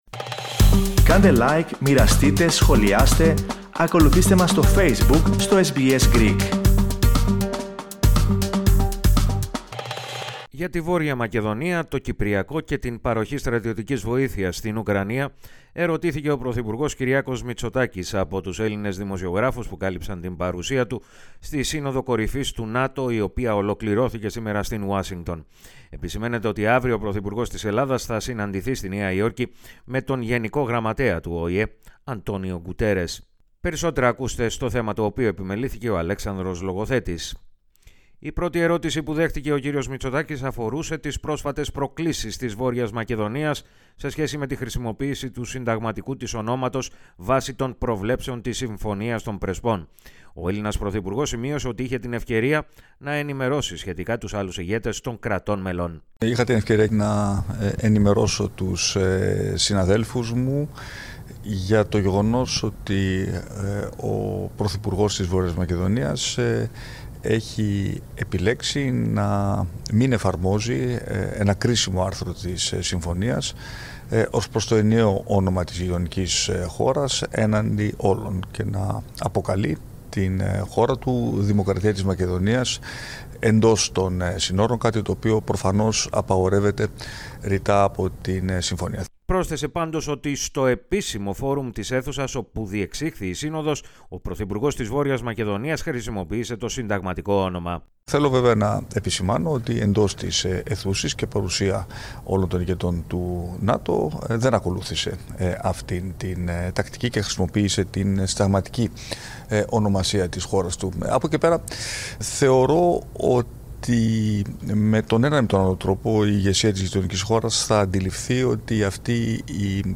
Για τη Βόρεια Μακεδονία, το Κυπριακό και την παροχή στρατιωτικής βοήθειας στην Ουκρανία, ερωτήθηκε ο πρωθυπουργός Κυριάκος Μητσοτάκης, από τους Έλληνες δημοσιογράφους που κάλυψαν την παρουσία του στη Σύνοδο Κορυφής του ΝΑΤΟ, η οποία ολοκληρώθηκε, σήμερα, στην Ουάσιγκτον.